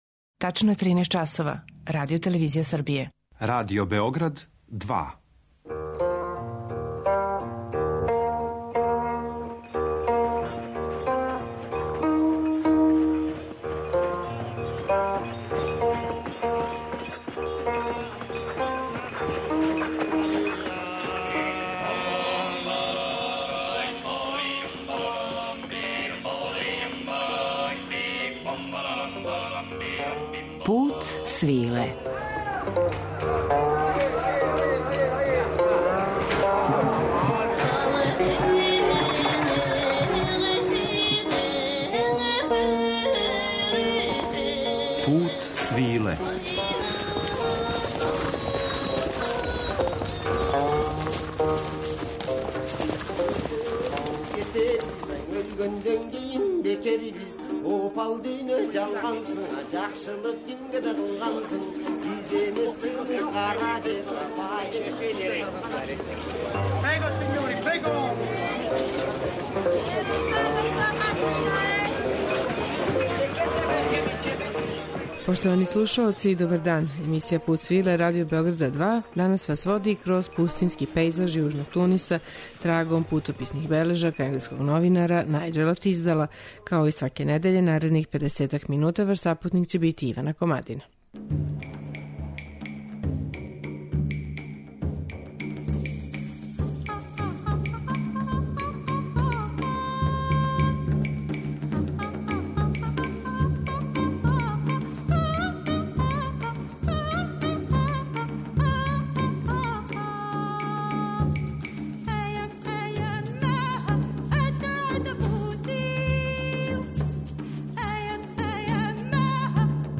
Шот ел Џерид уз музику у интерпретацији Ламие Бедуи и Галие Бенали
У ово пространство отиснућемо се у данашњем Путу свиле уз музичку пратњу две веома угледне даме тунижанске музике: певачице Ламие Бедуи и Галие Бенали.